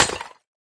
Minecraft Version Minecraft Version 1.21.5 Latest Release | Latest Snapshot 1.21.5 / assets / minecraft / sounds / block / decorated_pot / shatter1.ogg Compare With Compare With Latest Release | Latest Snapshot
shatter1.ogg